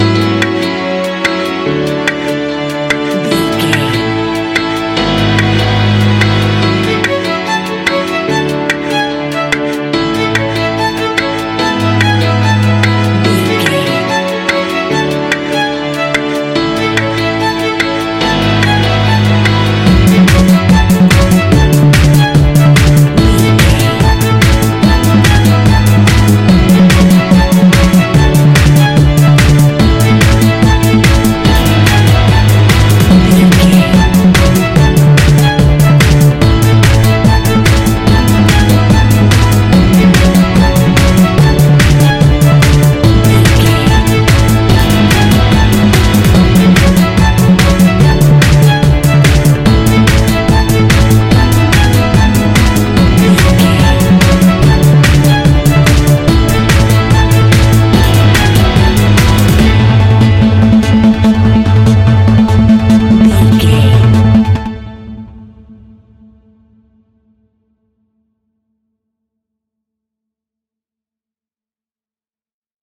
Ionian/Major
driving
energetic
uplifting
hypnotic
drum machine
synthesiser
violin
piano
acid house
electronic
uptempo
synth leads
synth bass